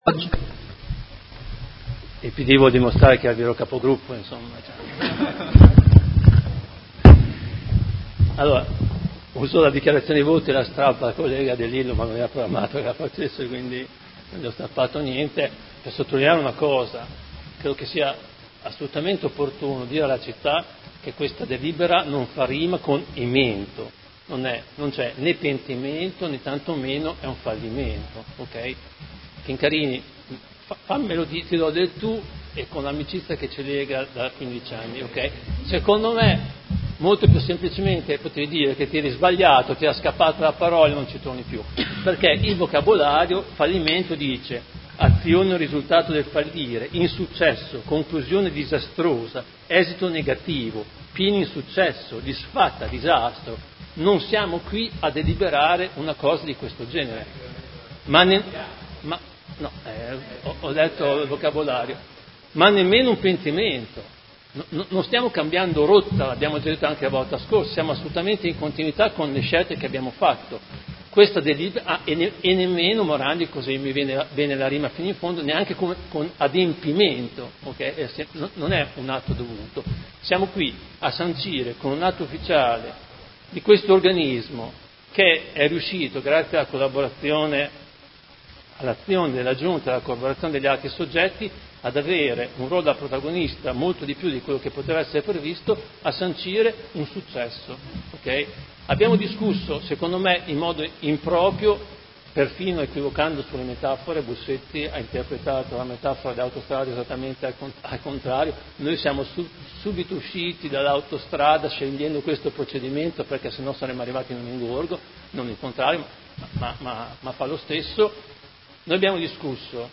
Fabio Poggi — Sito Audio Consiglio Comunale
Seduta del 25/1025 Dichiarazione di voto.